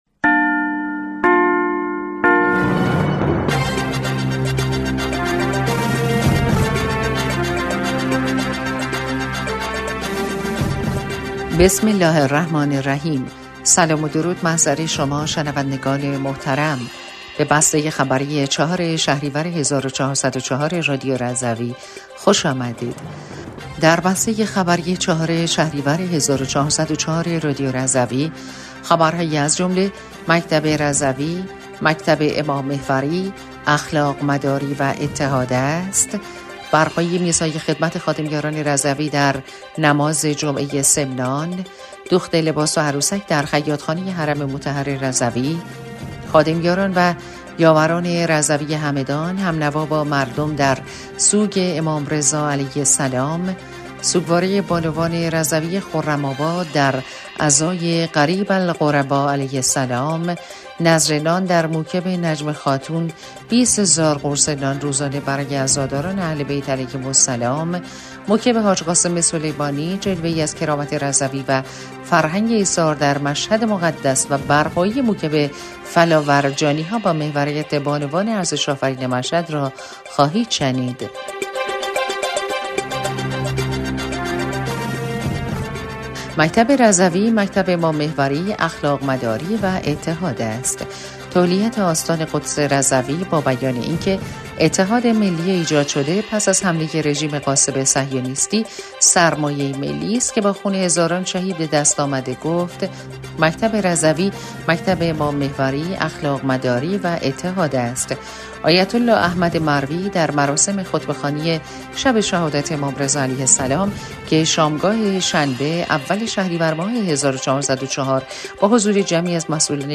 بسته خبری ۴ شهریور ۱۴۰۴ رادیو رضوی/